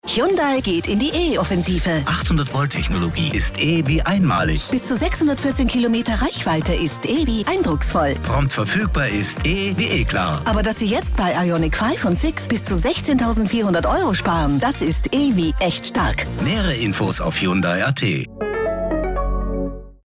Hyundai Radio Spot